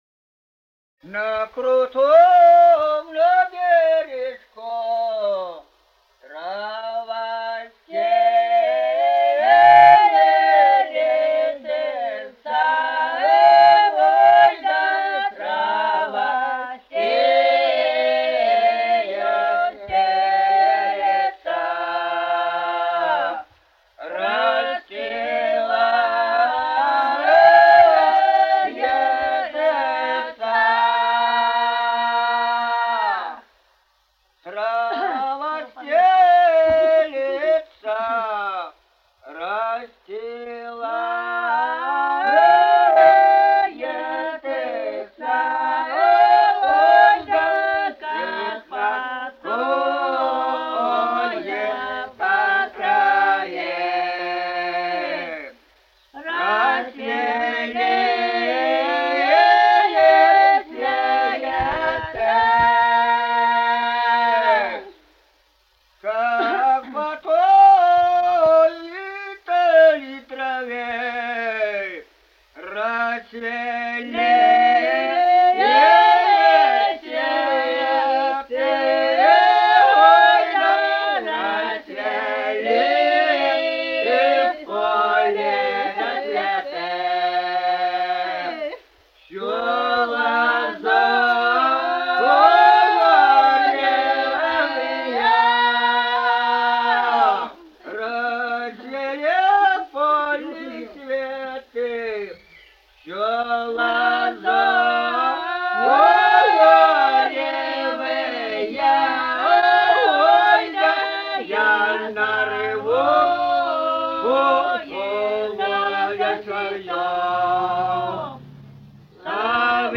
с. Коробиха Катон-Карагайского р-на Восточно-Казахстанской обл.